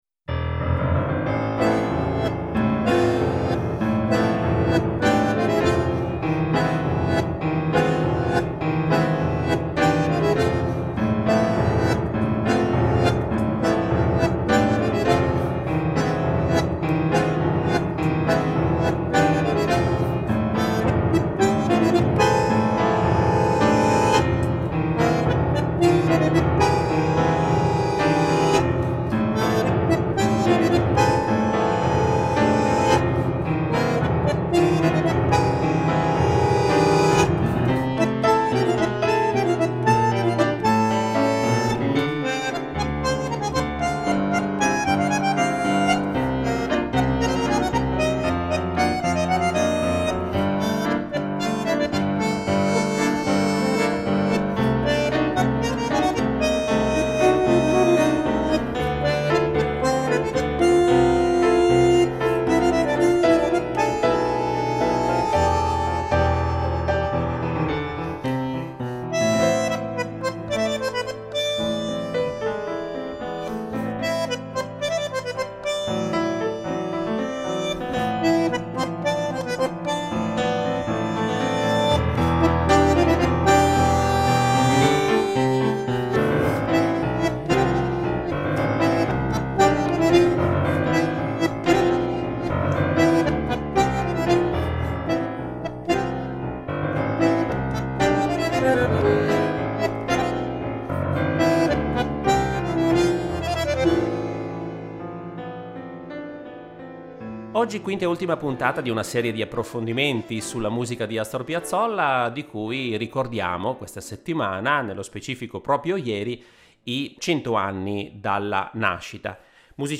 Musicalbox